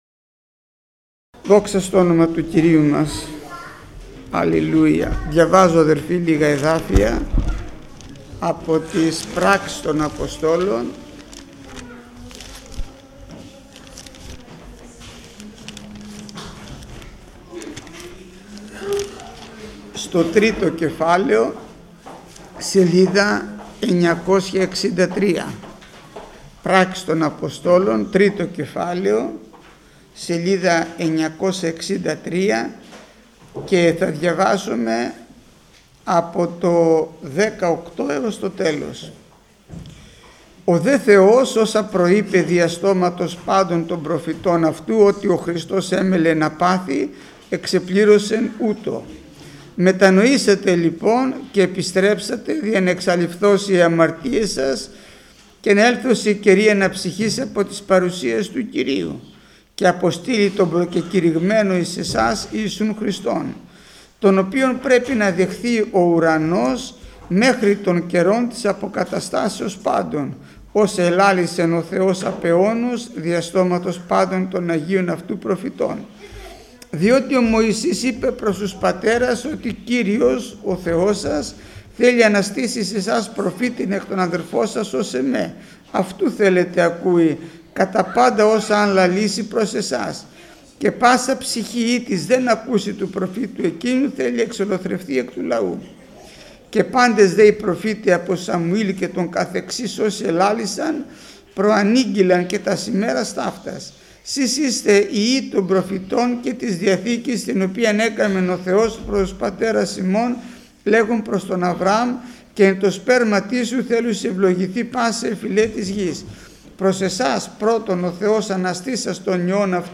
Μηνύματα Θείας Κοινωνίας